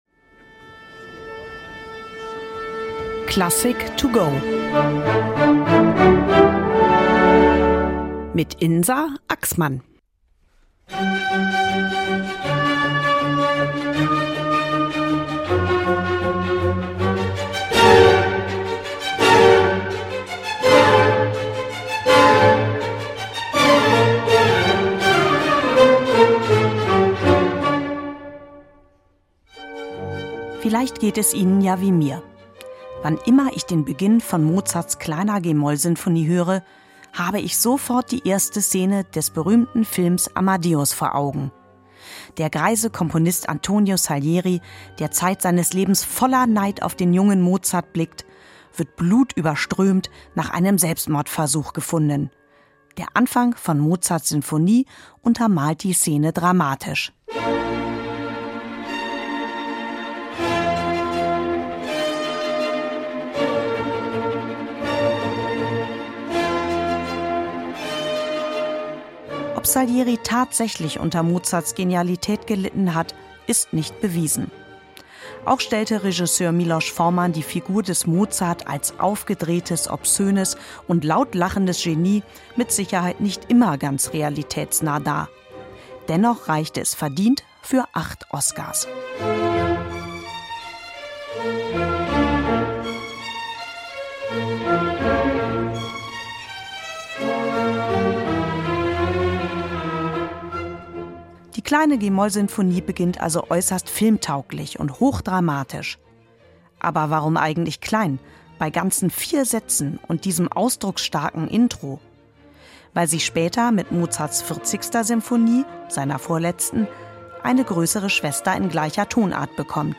Werkeinführung für unterwegs